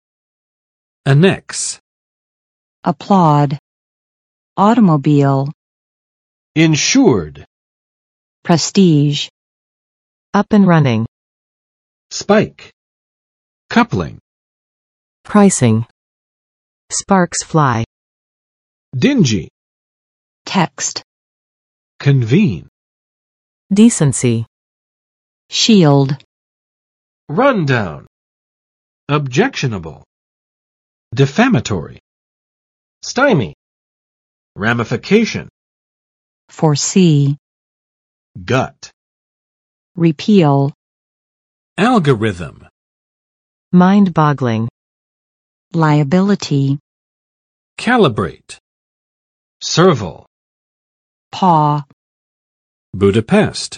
[əˋnɛks] v. 并吞，强占